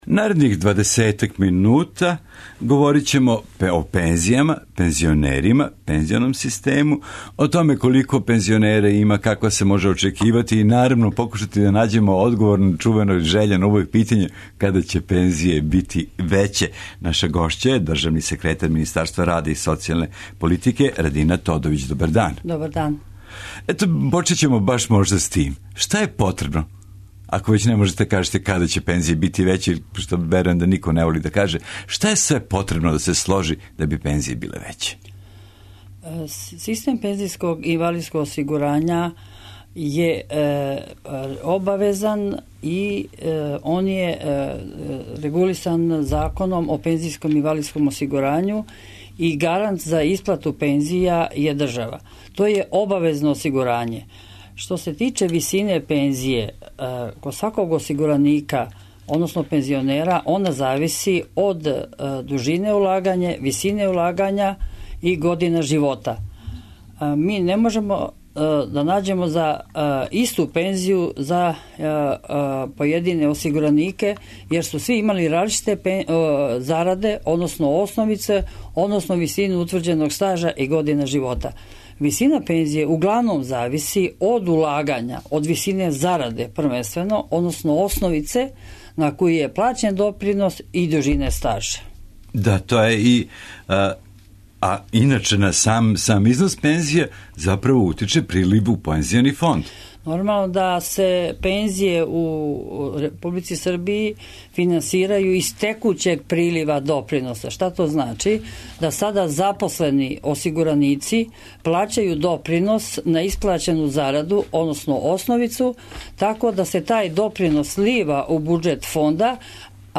О томе ћемо говорити са гошћом емисије, државним секретаром Министарства рада и социјалне политике Радином Тодовић.